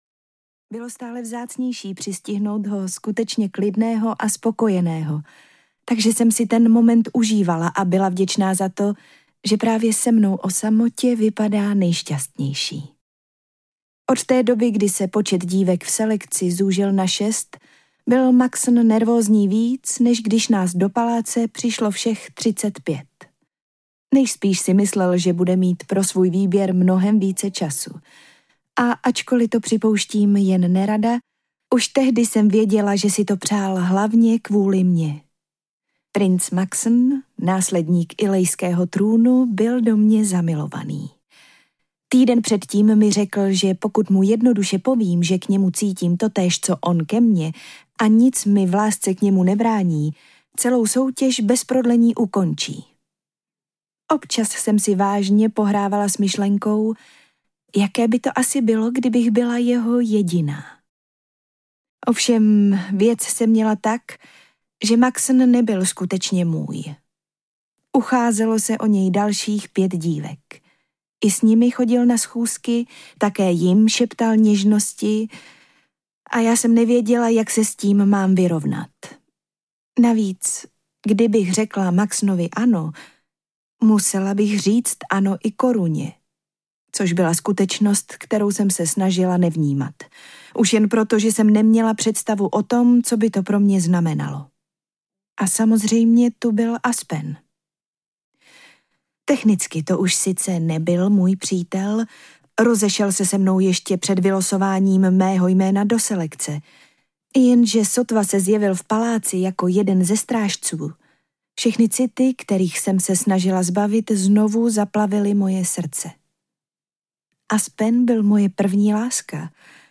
Elita audiokniha
Ukázka z knihy